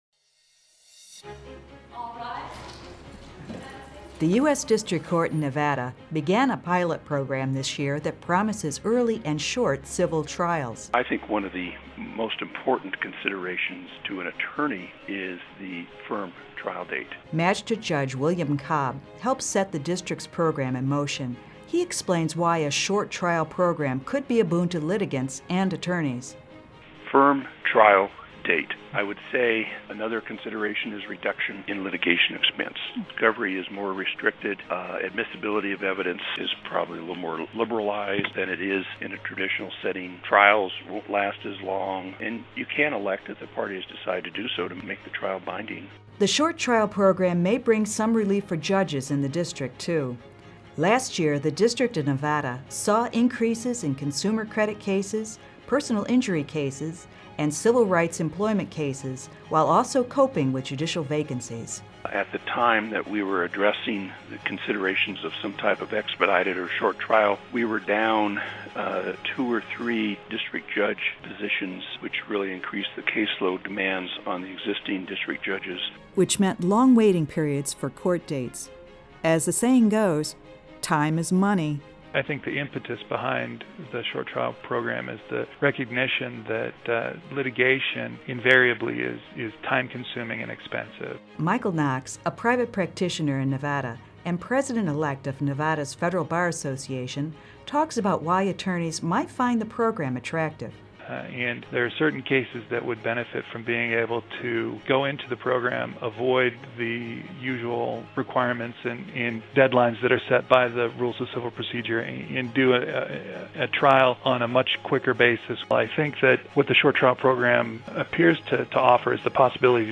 In this podcast, listen as Judge Cobb explains the short trial program and its benefits for attorneys and litigants.